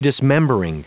Prononciation du mot dismembering en anglais (fichier audio)
Prononciation du mot : dismembering